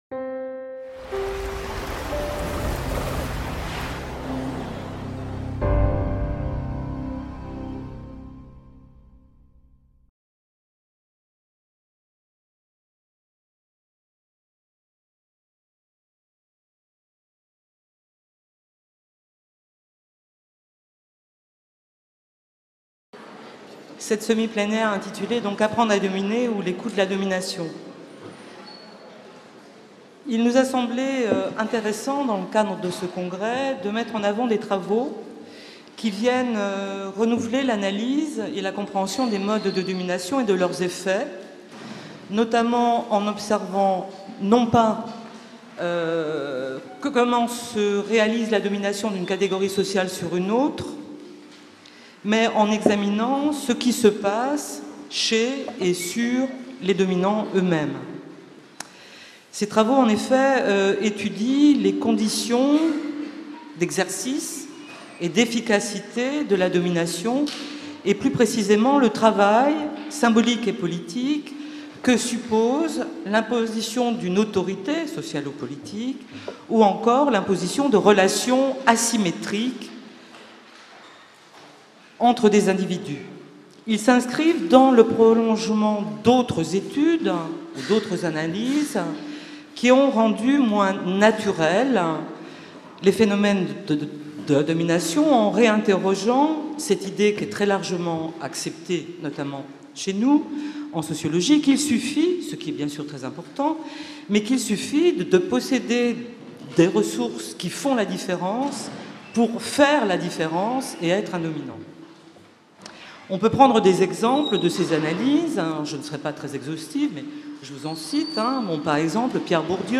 semi-plénière
L'UFR de Sociologie et le Centre Nantais de Sociologie (CENS) de l’Université de Nantes accueillaient du 2 au 5 septembre 2013 le 5e congrès international de l'association française de sociologie.